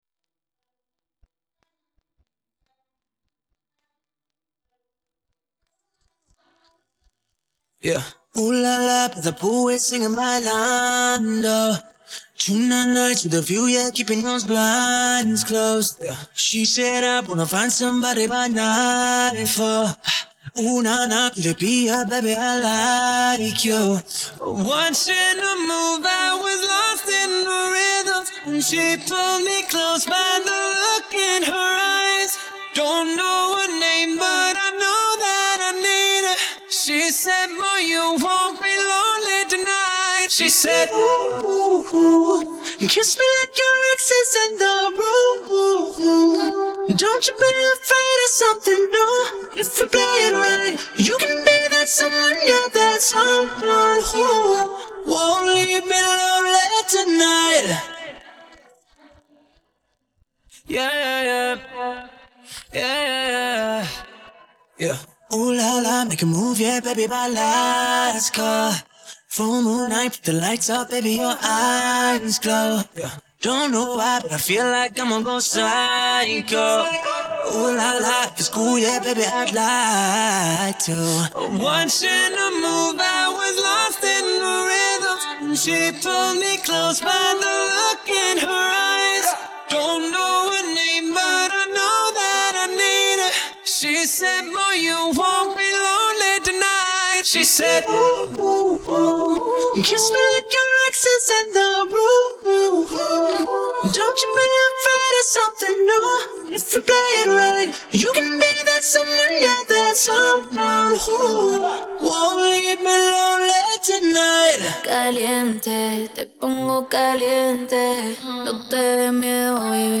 Voz humana